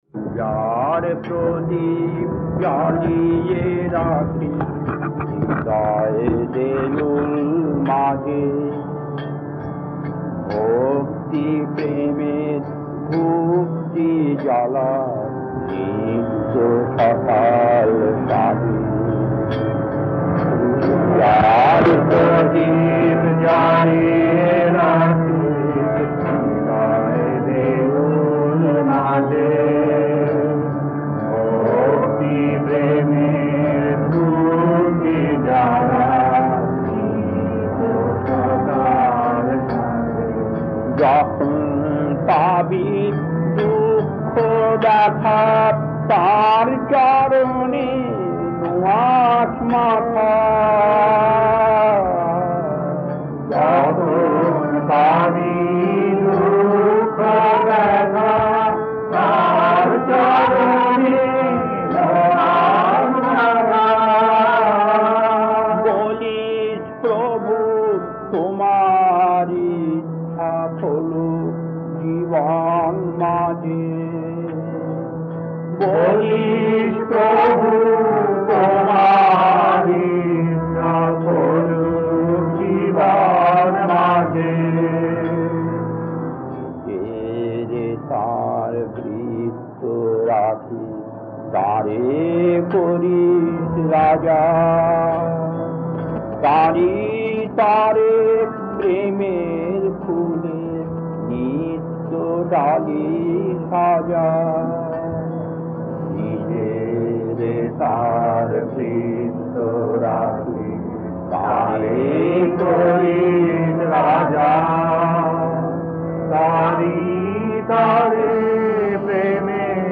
Kirtan D3-2A 1.